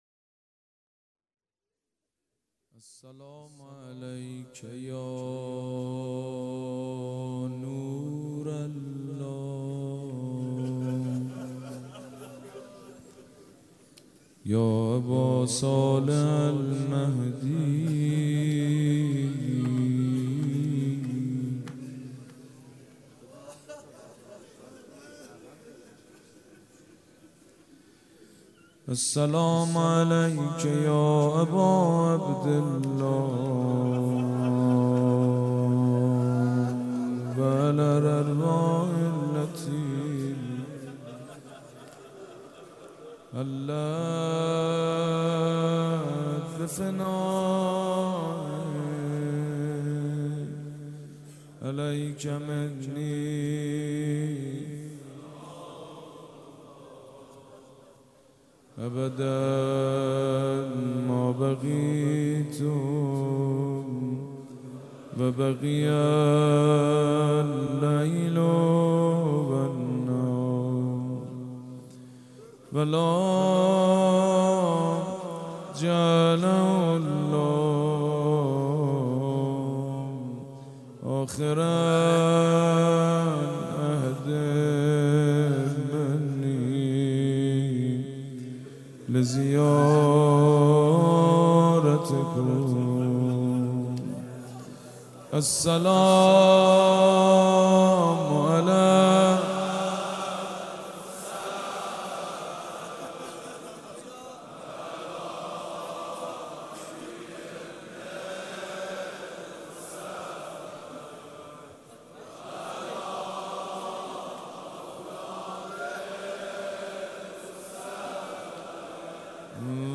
روضه مداح حاج سید مجید بنی فاطمه
مراسم عزاداری شب دوم